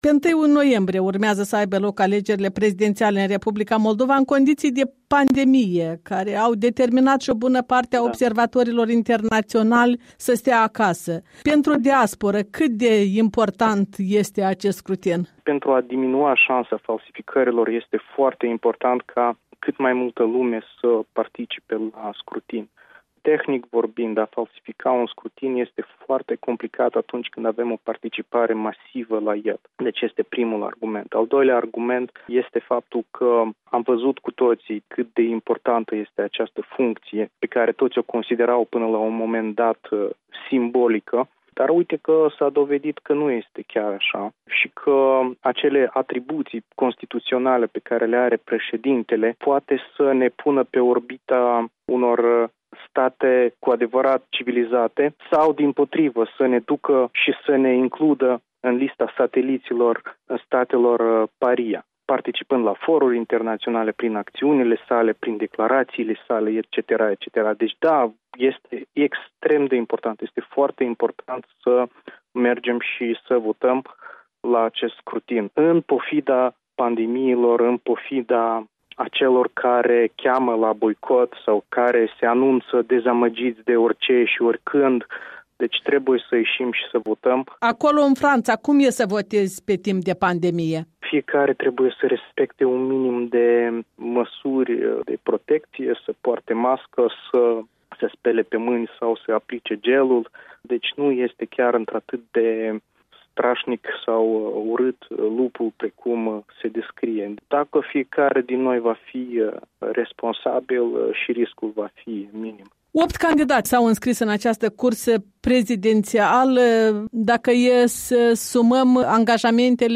Interviu electoral.